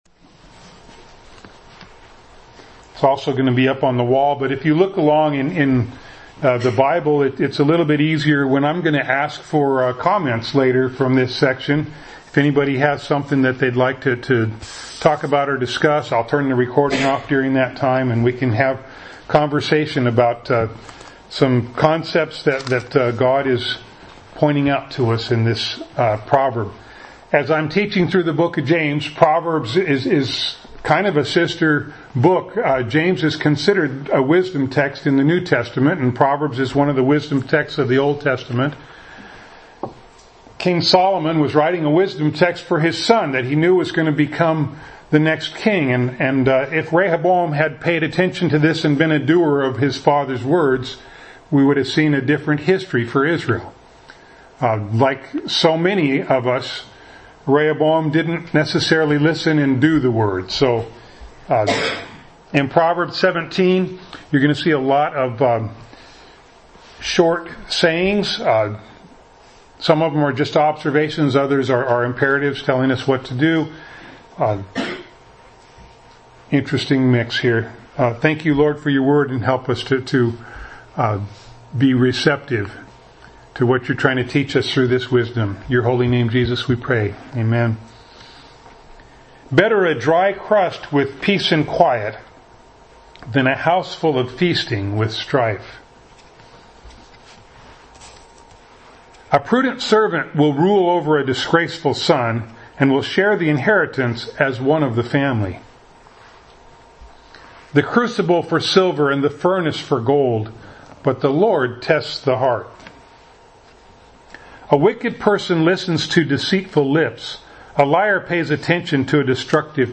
Passage: James 1:22 Service Type: Sunday Morning